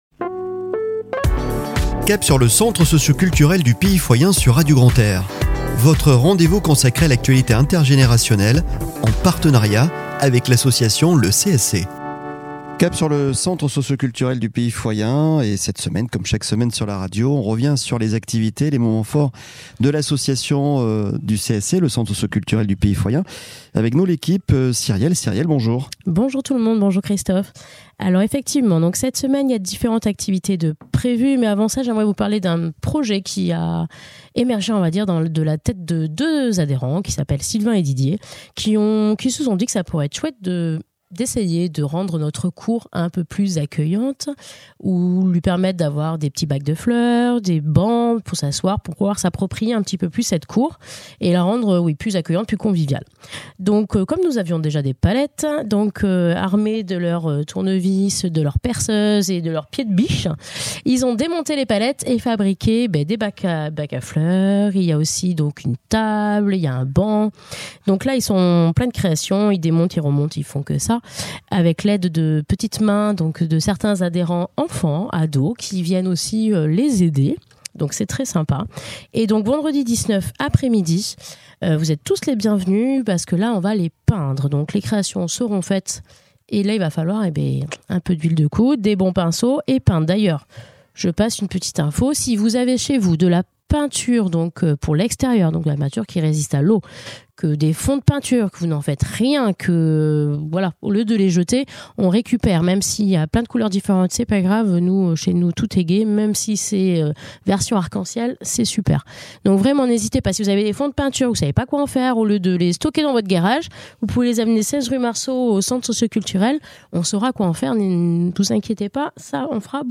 "Cap sur le Centre Socioculturel du Pays Foyen" un rendez-vous consacré à toute l'actualité inter générationnelle et les temps forts proposés sur les 20 communes du Pays Foyen par le Centre Socioculturel. Une chronique
avec la parole aux adhérents